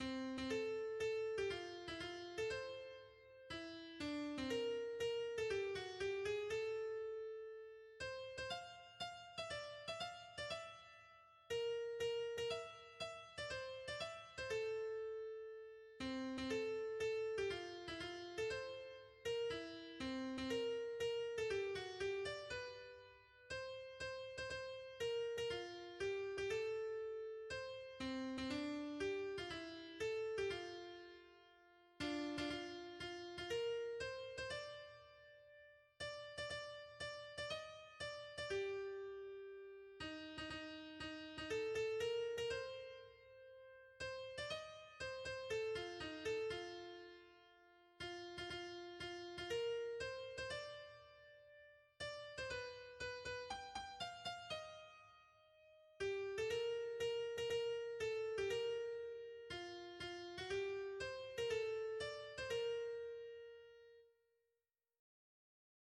Áudio Karaoke -